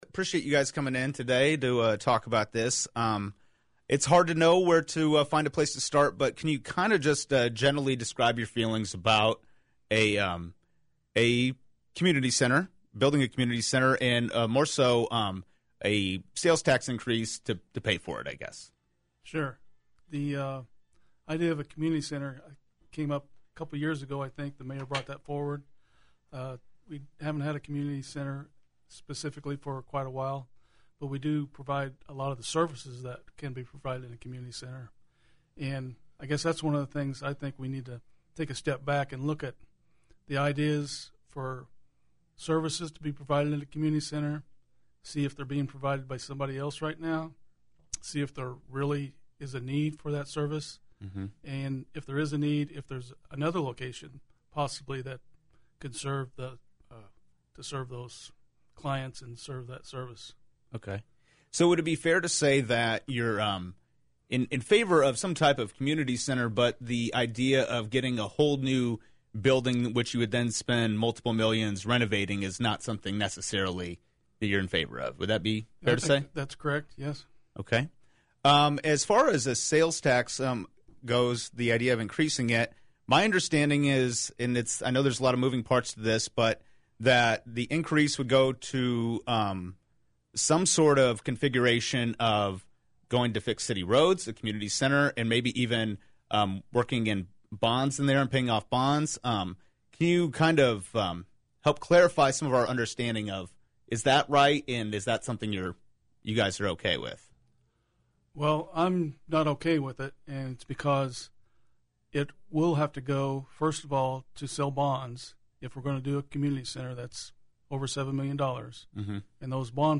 Galesburg City Council Members Bradley Hix (Ward 1) and Larry Cox (Ward 7) stopped by Galesburg’s Evening News Wednesday to talk about their opinions on a proposed increase in the home rule sales tax, a proposed community center, and all the moving pieces behind both topics.